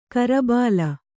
♪ karabāla